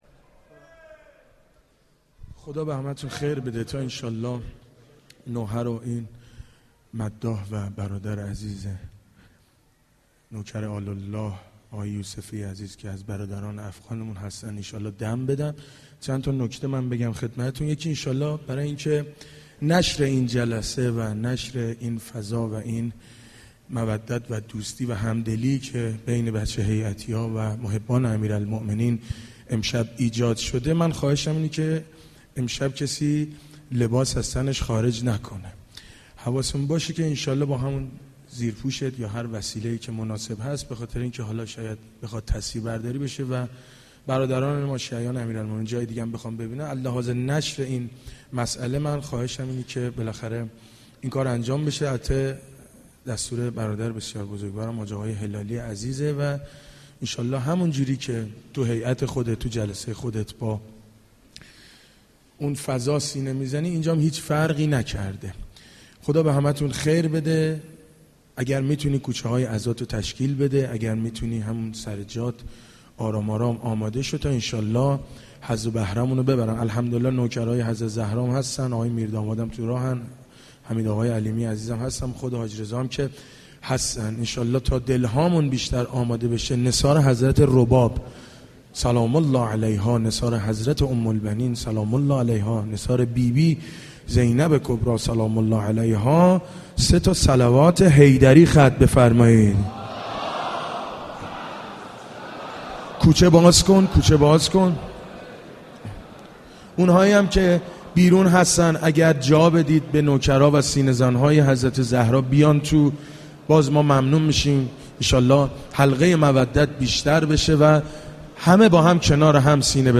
در مهدیه تهران برگزار شد.